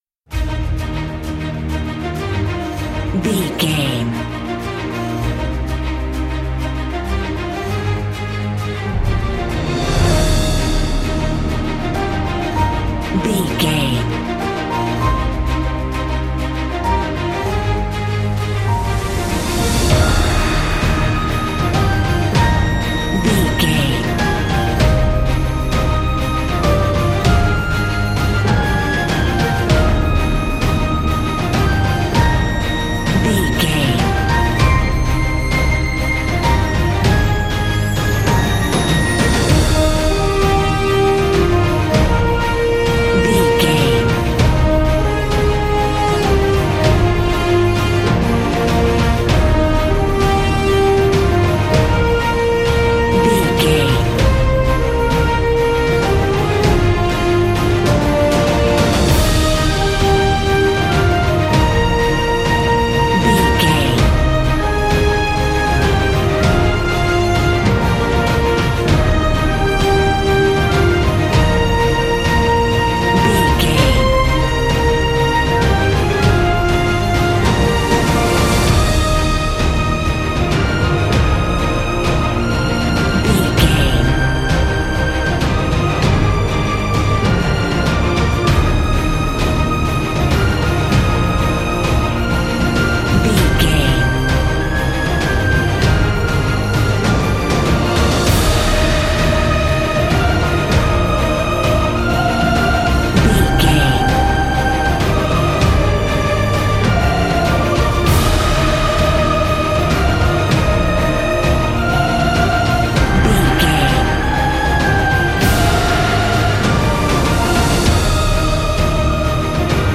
Ionian/Major
E♭
cinematic
energetic
brass
cello
drums
flute
strings
trumpet
violin